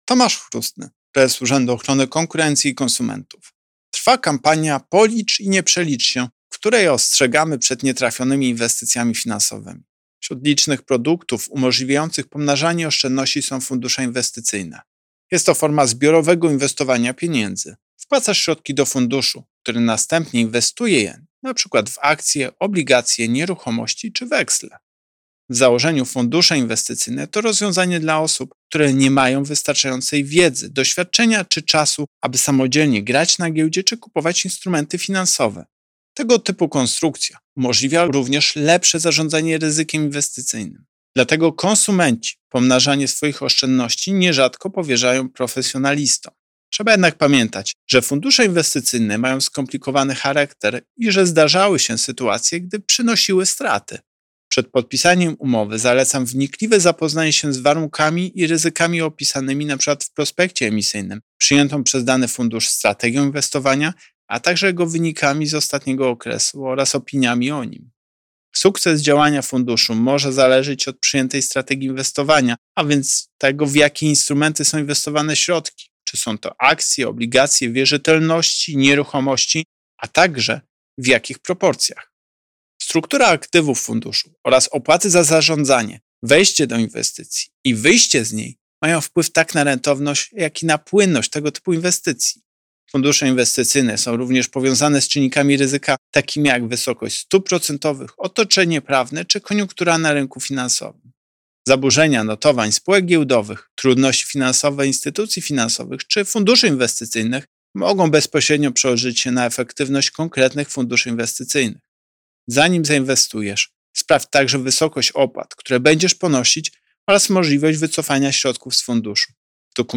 Pobierz wypowiedź Prezesa UOKiK Tomasza Chróstnego Rodzaje funduszy inwestycyjnych Fundusze inwestycyjne są instrumentami, w ramach których połączone środki indywidualnych inwestorów są wspólnie inwestowane w różne instrumenty.